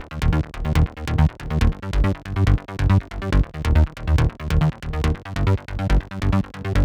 VTS1 Space Of Time Kit Bassline